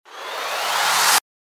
FX-981-WIPE
FX-981-WIPE.mp3